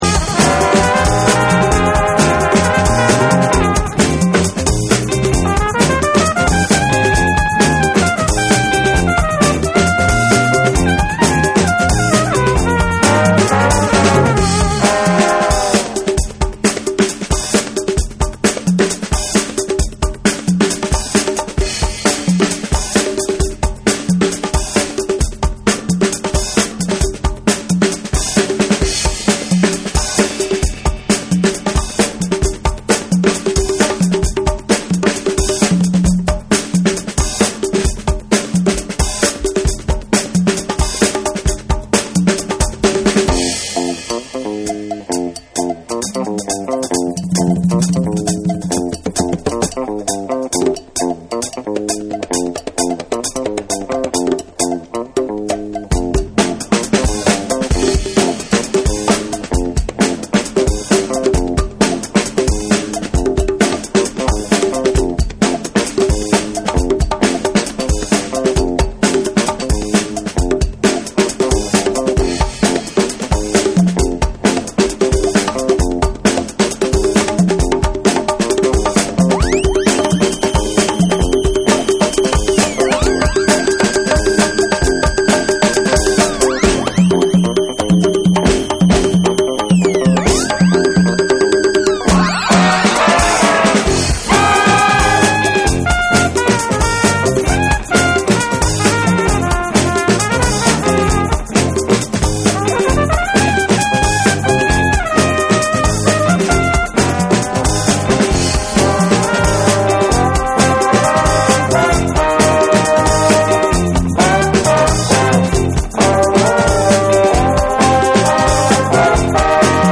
クンビアにも通じるリズム感にティンバレスがリズミカルに炸裂するラテン・グルーヴ
BREAKBEATS / ORGANIC GROOVE